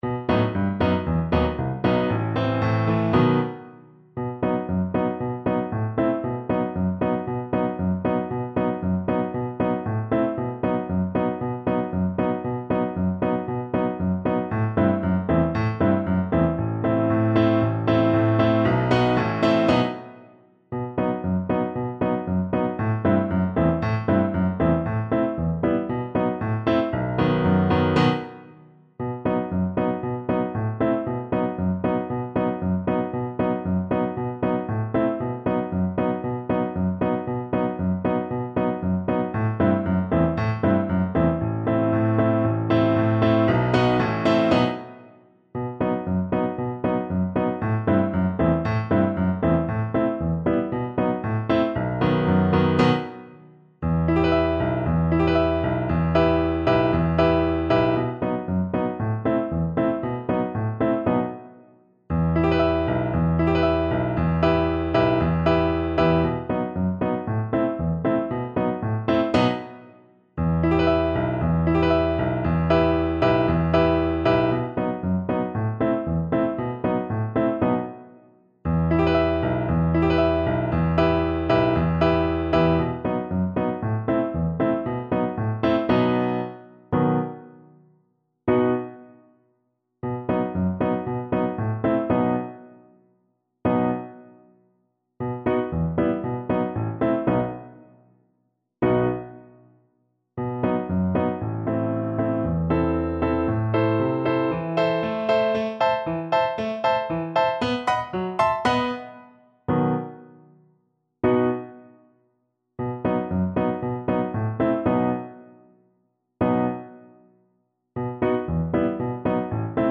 Play (or use space bar on your keyboard) Pause Music Playalong - Piano Accompaniment Playalong Band Accompaniment not yet available reset tempo print settings full screen
E minor (Sounding Pitch) (View more E minor Music for Viola )
Allegro =c.116 (View more music marked Allegro)